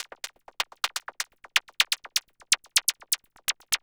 tx_perc_125_stereozappers1.wav